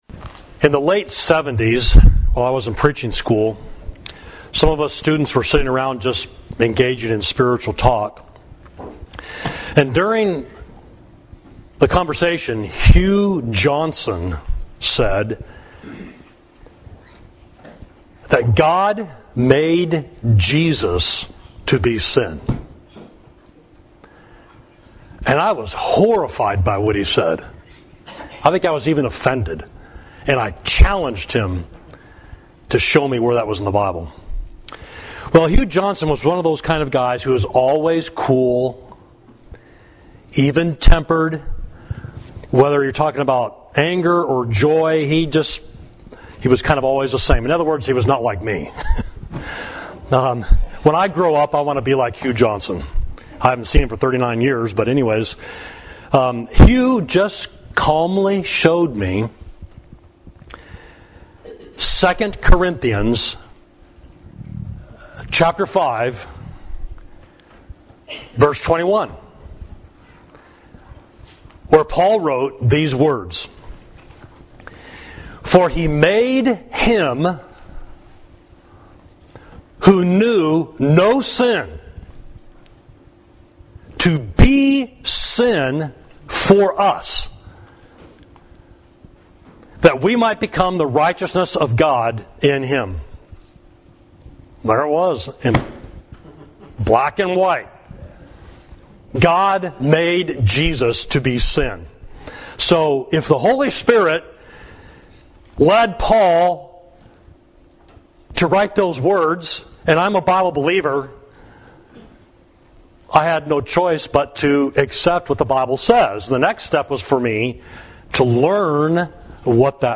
Sermon: Jesus Was Made Sin for Us